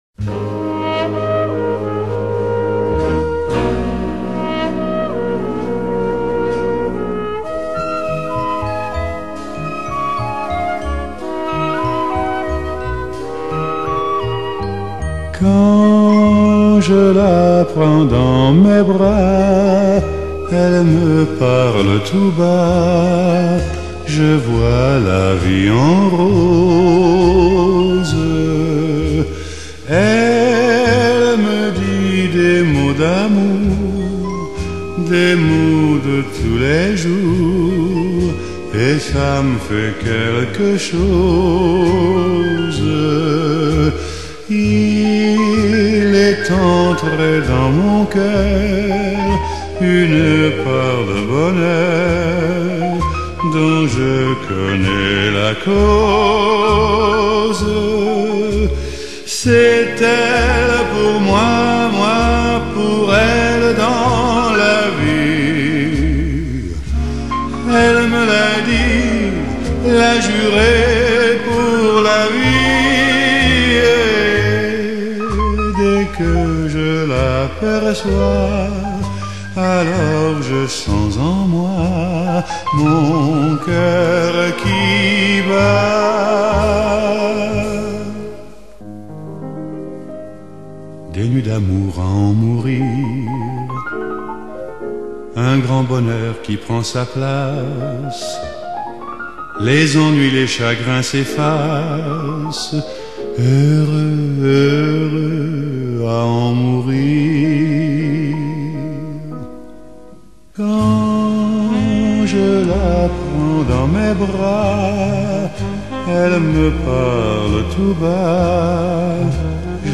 Genre: Pop, French, Chanson